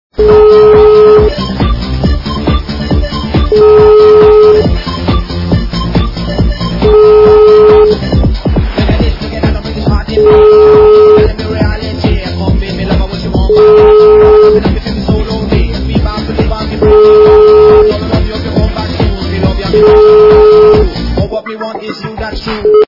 западная эстрада
качество понижено и присутствуют гудки.